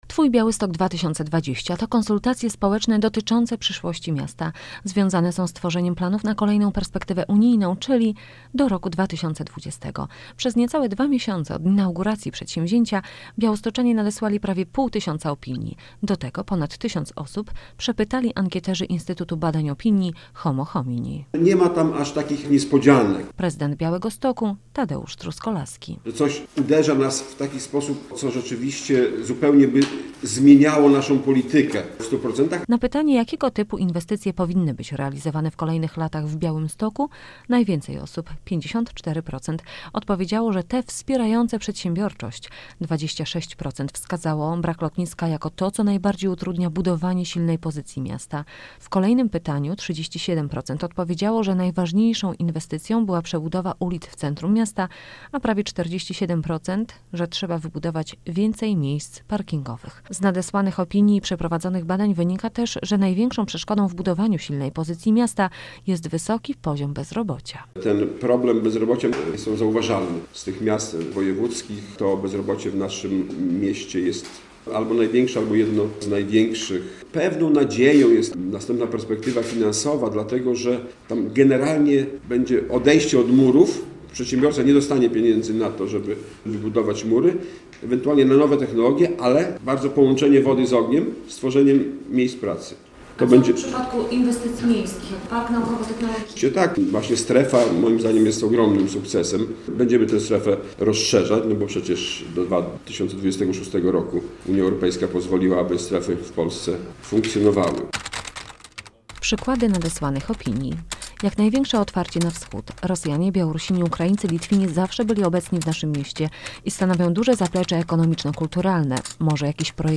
Białostoczanie o życiu w mieście - relacja